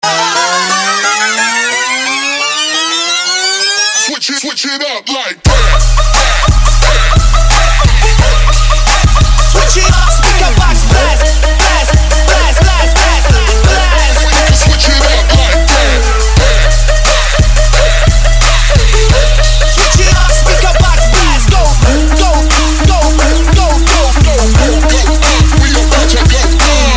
• Качество: 128, Stereo
мужской голос
мощные
remix
Electronic
Dubstep
breakbeat